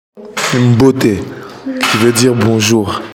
uitspraak